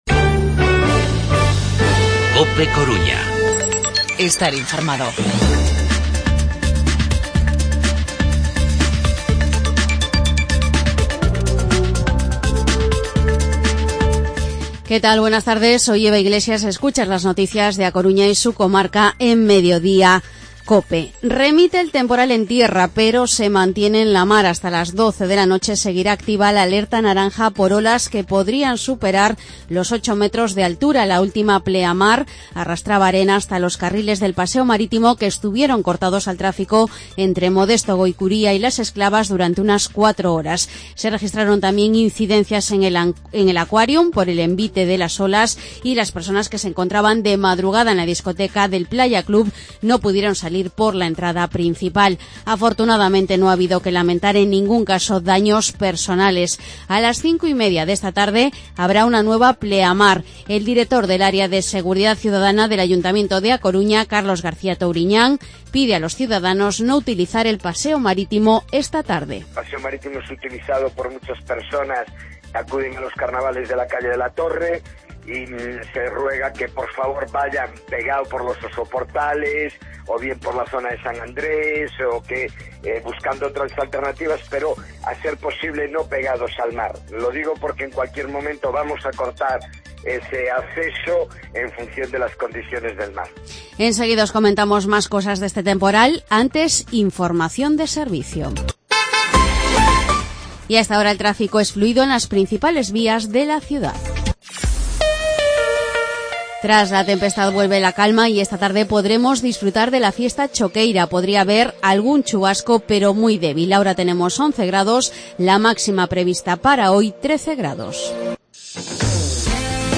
Informativo mediodía 28 de febrero de 2017